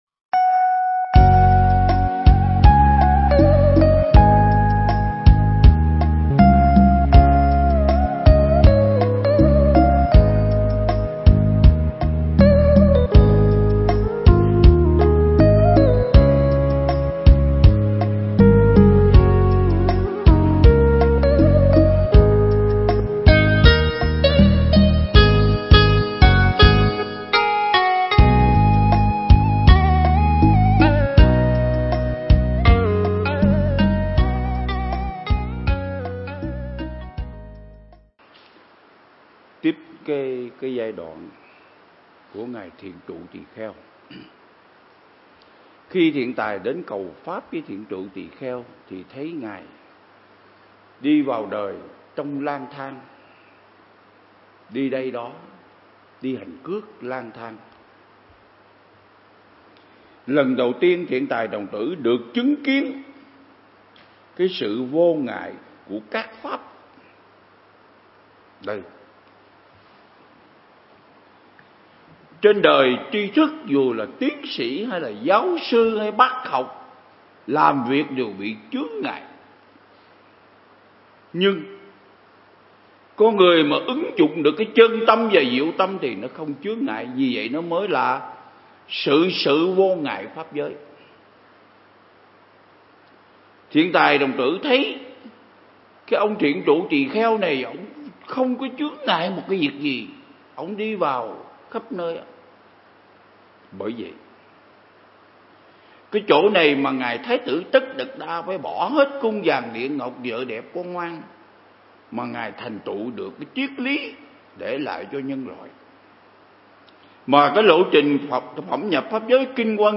Mp3 Pháp Thoại Ứng Dụng Triết Lý Hoa Nghiêm Phần 31